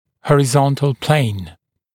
[ˌhɔrɪ’zɔntl pleɪn][ˌхори’зонтл плэйн]горизонтальная плоскость